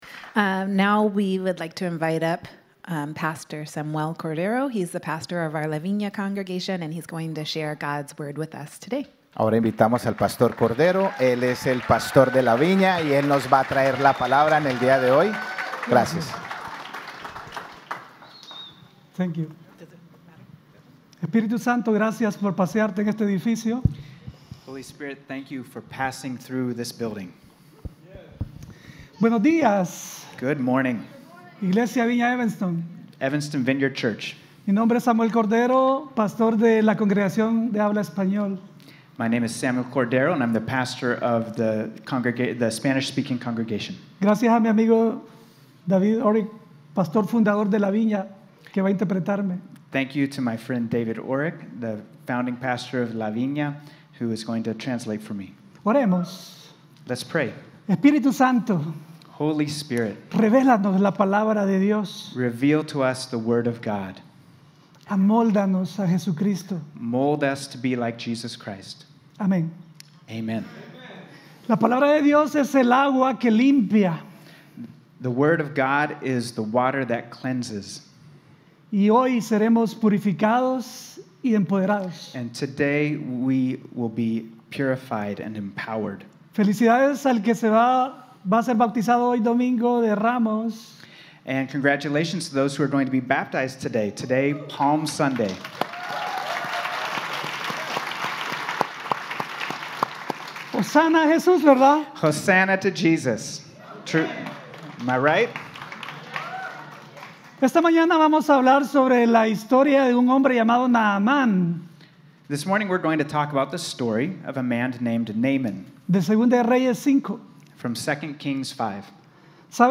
4-2-23-Sermon.mp3